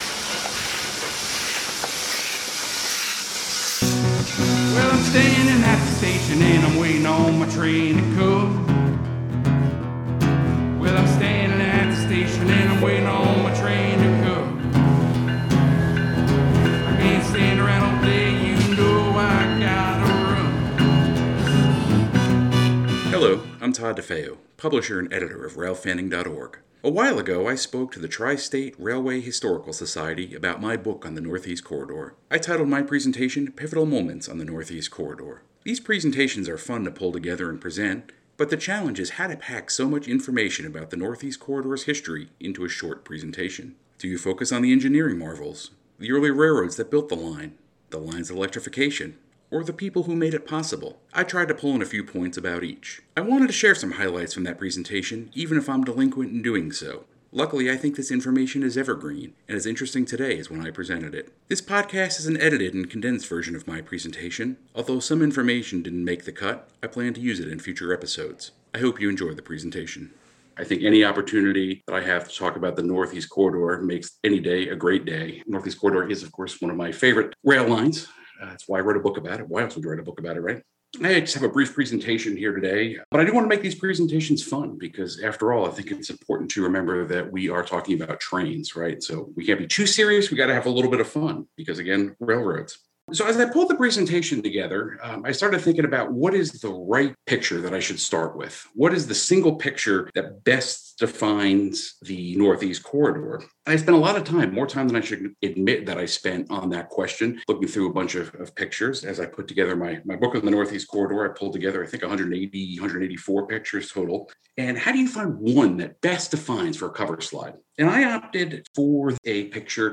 This podcast is an edited and condensed version of my presentation.
Sound Effects Diesel Horn: Recorded at the Southeastern Railway Museum on November 14, 2020. Steam Train: 1880s Train, recorded September 12, 2020, in Hill City, South Dakota. Show Notes This is an edited and condensed version of a presentation to the Tri-State Railway Historical Society, recorded on July 15, 2021.